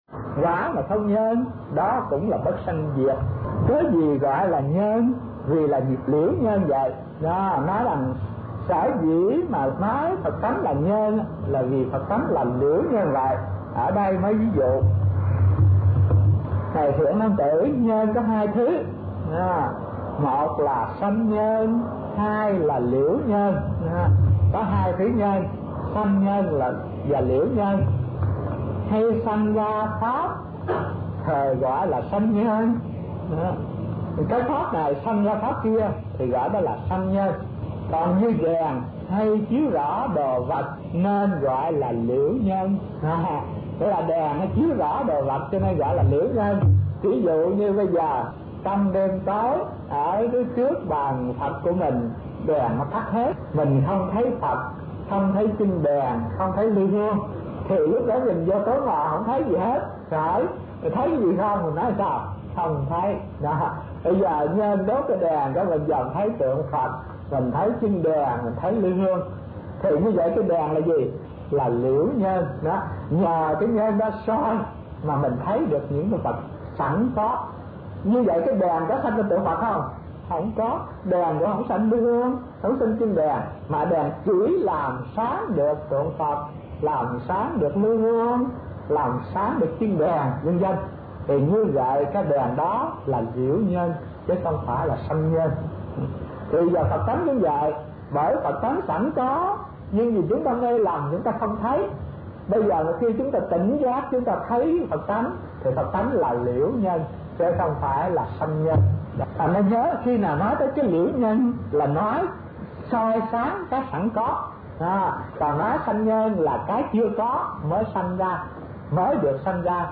Kinh Giảng Đại Bát Niết Bàn - Thích Thanh Từ